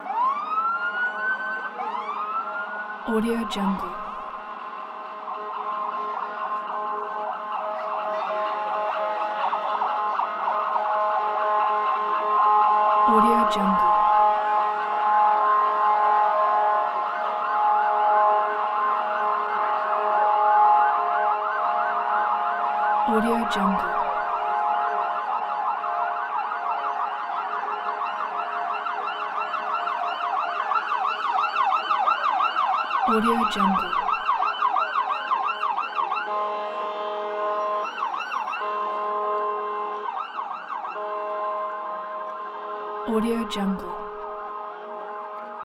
دانلود افکت صدای آژیر پلیس عصبانی
افکت صدای آژیر پلیس کارتونی یک گزینه عالی برای هر پروژه ای است که به صداهای شهری و جنبه های دیگر مانند پلیس، آژیر و زنگ هشدار نیاز دارد.
Sample rate 16-Bit Stereo, 44.1 kHz
Looped No